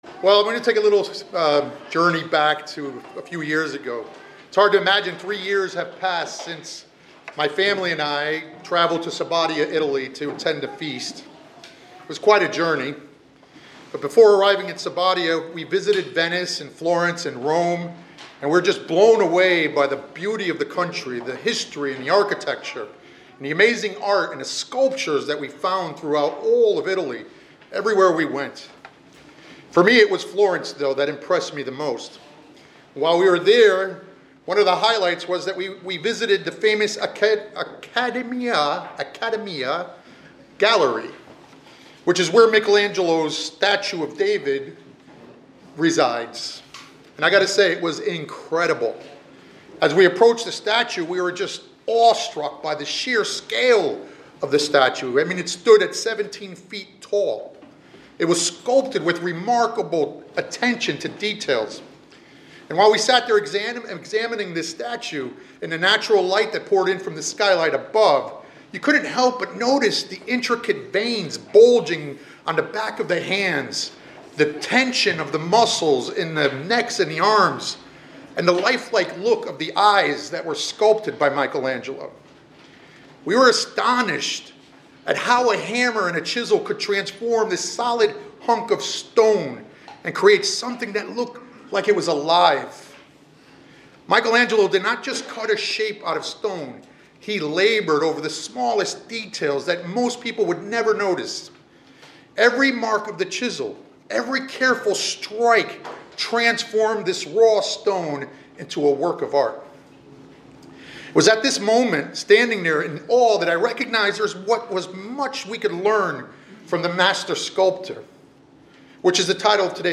This sermonette "The Master Sculptor" compares God’s work in our lives to Michelangelo sculpting the statue of David, emphasizing that God sees our true potential beyond our flaws, actively transforms us through life’s challenges, and assures us that the process will lead to a spiritual masterpiece ready for His Kingdom, encouraging us to trust and yield to His shaping hand.